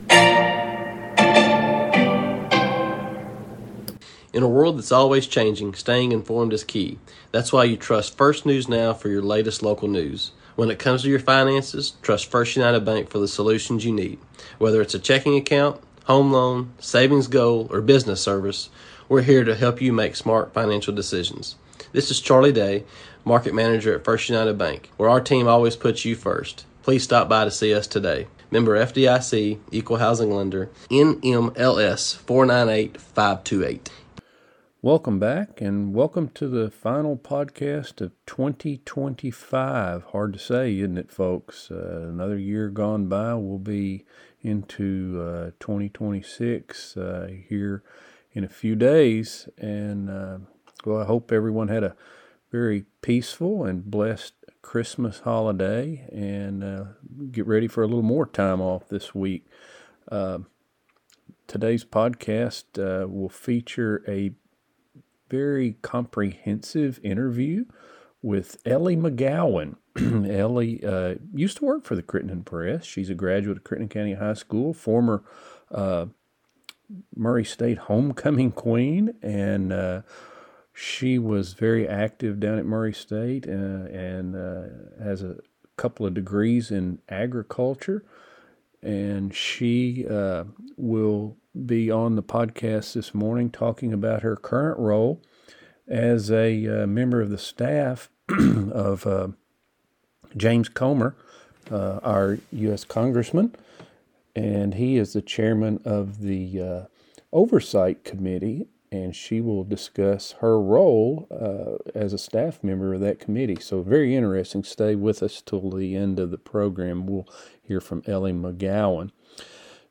Powered by 1st United Bank LISTEN NOW News | Sports | Interviews On Today's show